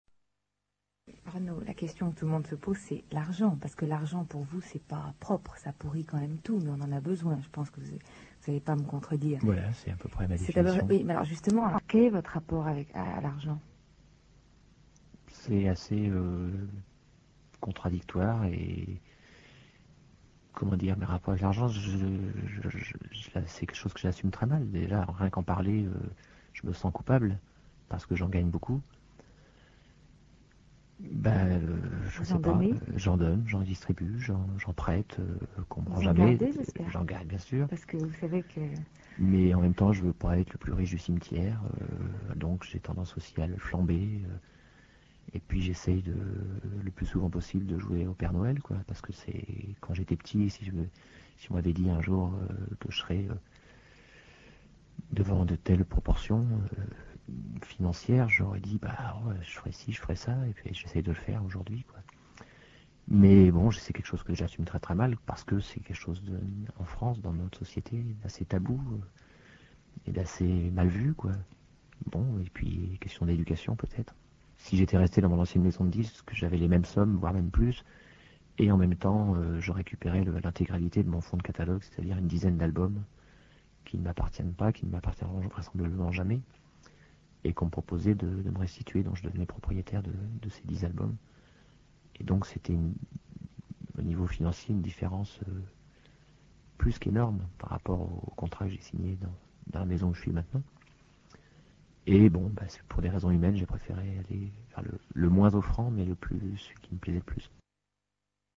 Interview de Renaud à RTL le 9 octobre 1989